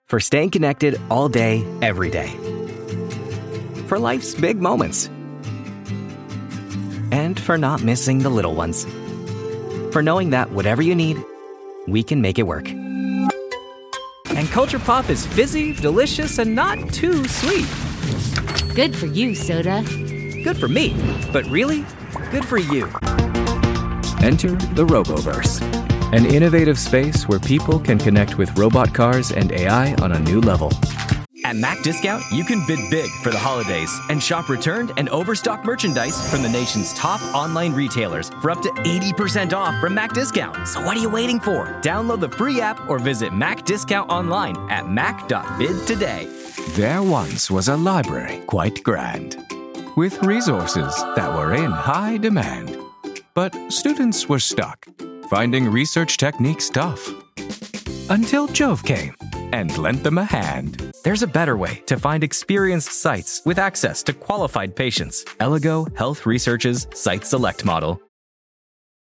Voice over
Microphones: Neumann TLM 103 Condenser – Synco MIC-D2 hypercardioid shotgun mic